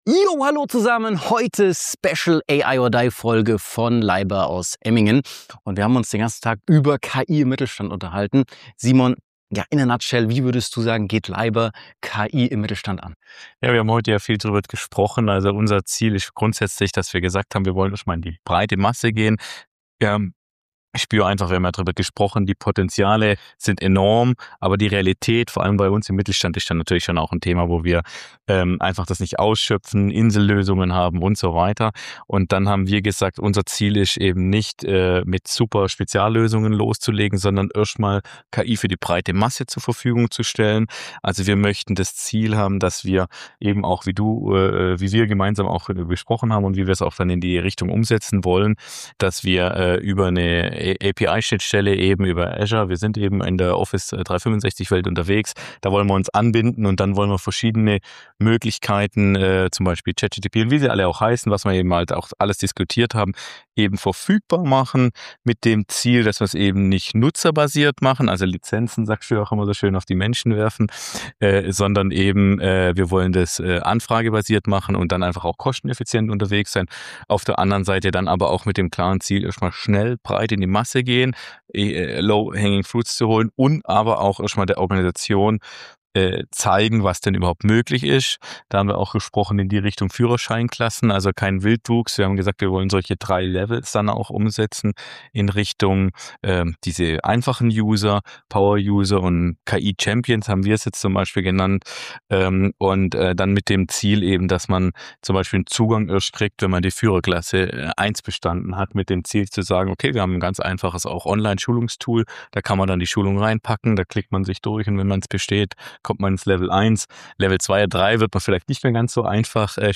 In dieser Special-Folge von AI or DIE sprechen wir live aus dem Mittelstand – direkt aus einem KI-Workshop bei der Leiber Group.